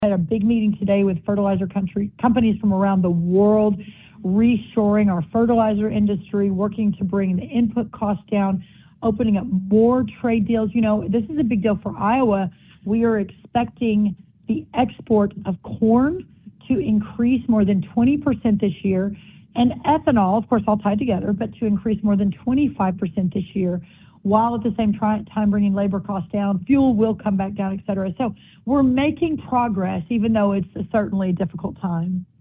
In an interview with the Iowa Agribusiness Radio Network, U.S. Agriculture Secretary Brooke Rollins said efforts are underway to address rising input costs while also working to strengthen demand for U.S. commodities.